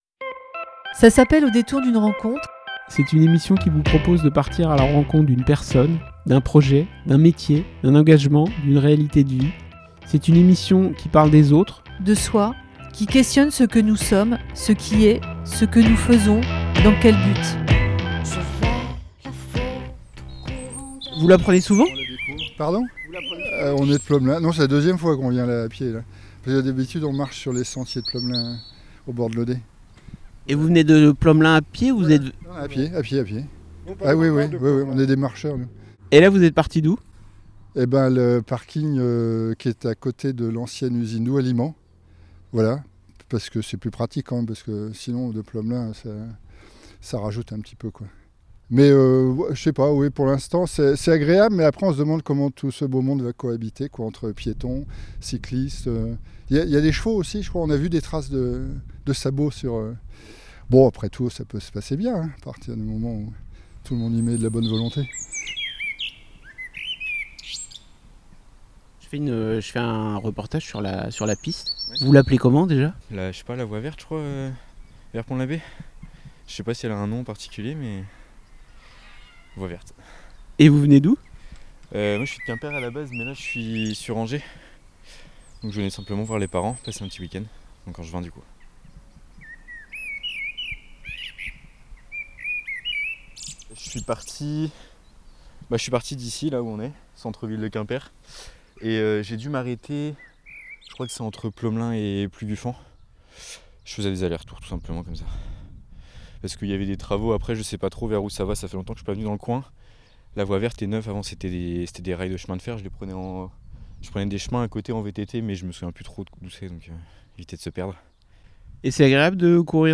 Attendue depuis plusieurs années, une voie verte permet maintenant de relier Quimper au Pays Bigouden, sur le tracé d'une ancienne voie ferrée. On y rencontre poussettes, vélos, grand-mères, marathoniennes ou semi-marathoniens, coureurs et coureuses plus tranquilles, marcheurs, cavaliers, promeneurs de chiens, chats. Nous avons tendu notre micro pour recueillir leurs voix. Entre deux chants d'oiseaux, vous pourrez entendre bavardages, respirations, voix essoufflées ou encore aboiements.